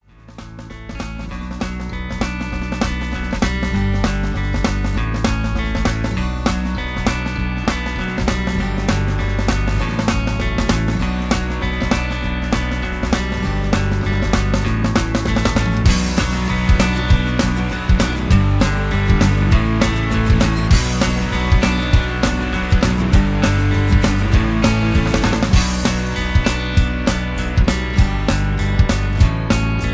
(Key-Dm)
Just Plain & Simply "GREAT MUSIC" (No Lyrics).